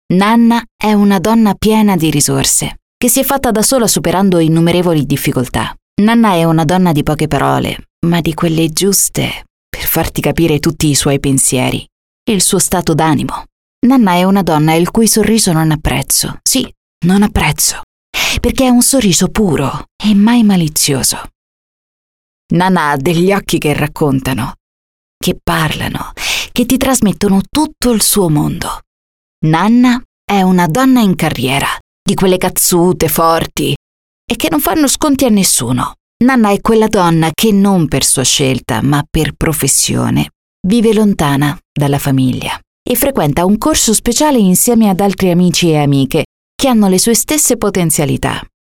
I have my own recording studio, equipped with the most advanced devices on the market.
Sprechprobe: eLearning (Muttersprache):
I record and mix everyday full day into my real professional home studio.